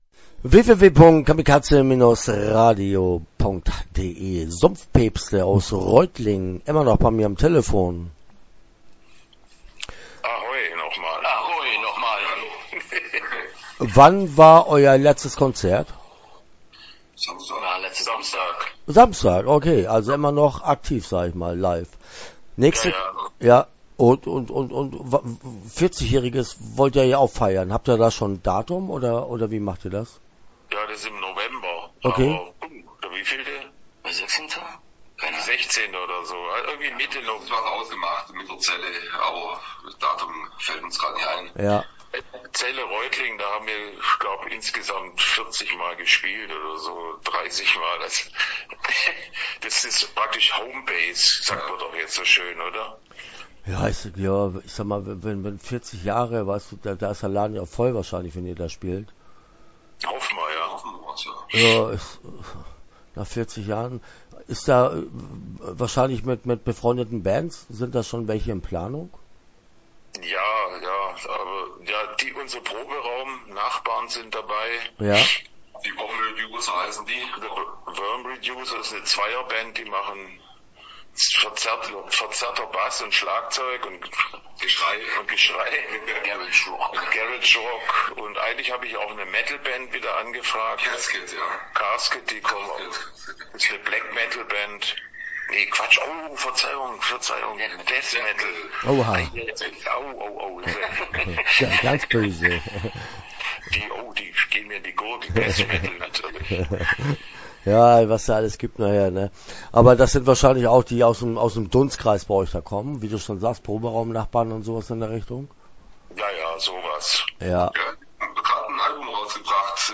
Start » Interviews » Sumpfpäpste